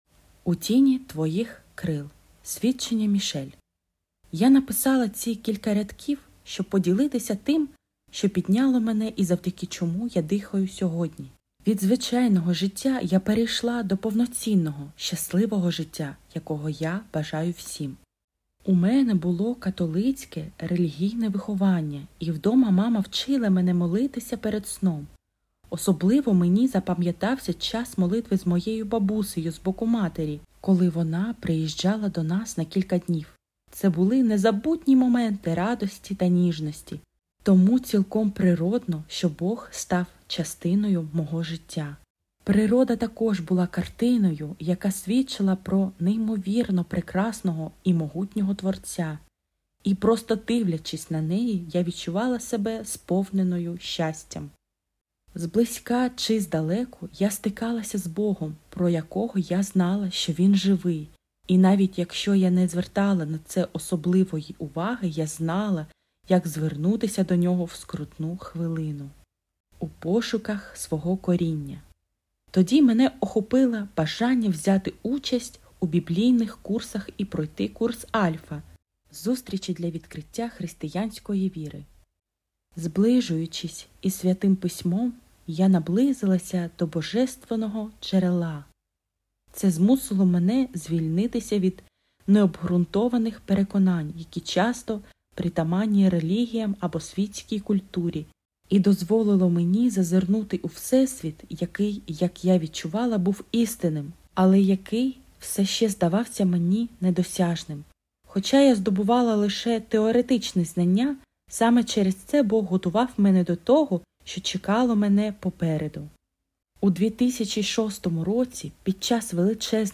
Аудіо, Свідчення